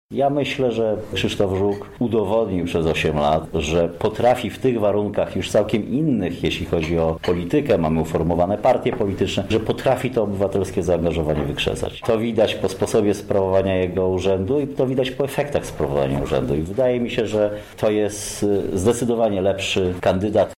– mówi Włodzimierz Karpiński, przewodniczący PO